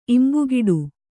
♪ imbugiḍu